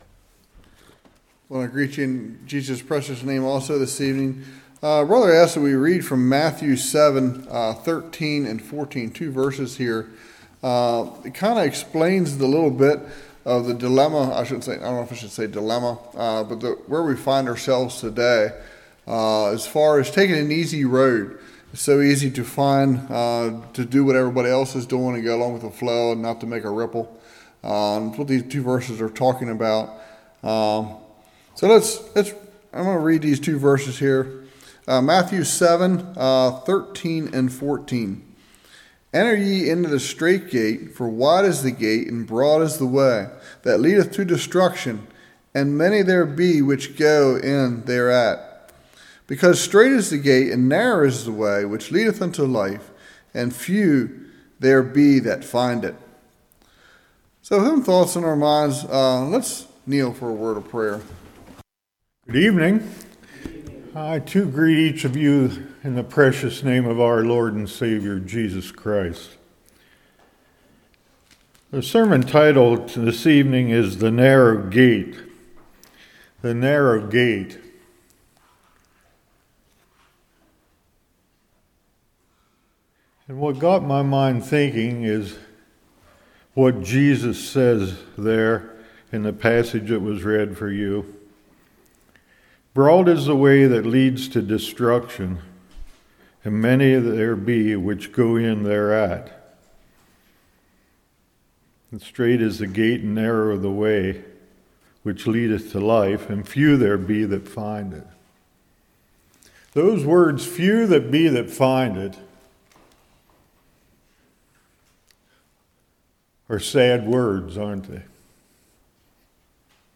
Matthew 7:13-14 Service Type: Evening God desires all to enter into heaven.